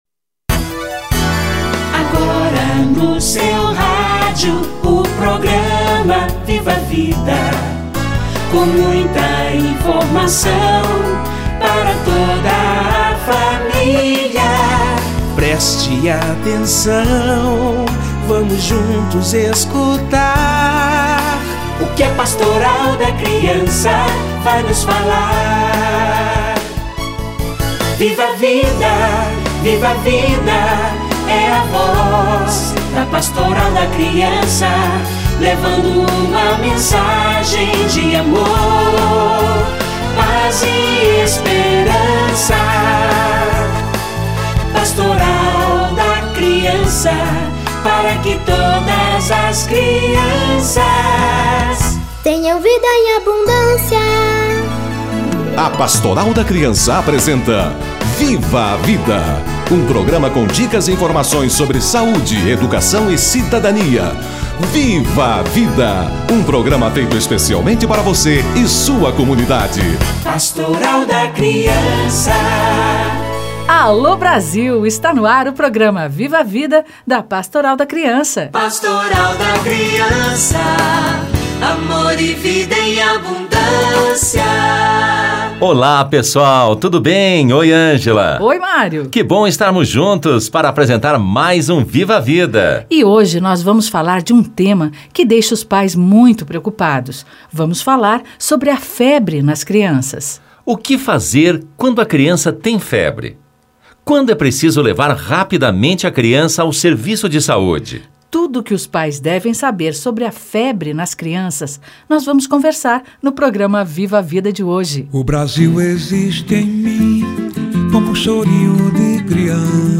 Febre nas crianças - Entrevista